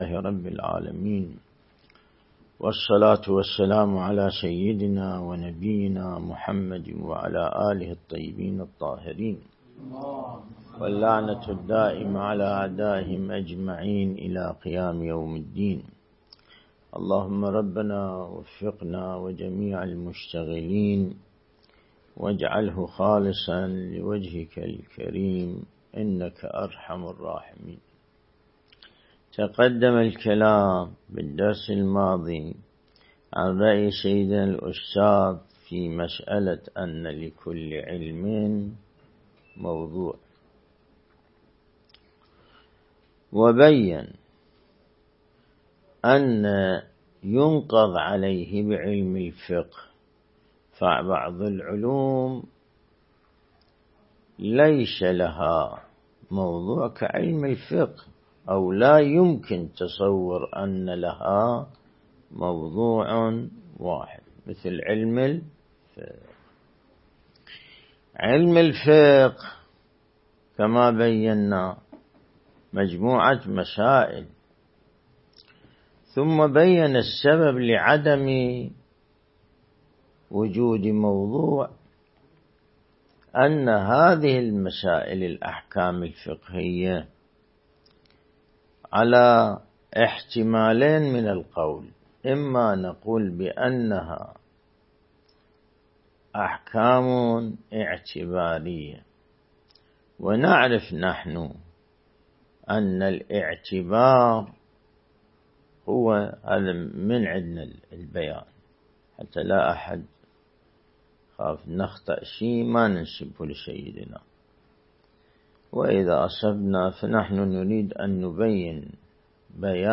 درس البحث الخارج الأصول (40) | الموقع الرسمي لمكتب سماحة آية الله السيد ياسين الموسوي «دام ظله»
النجف الأشرف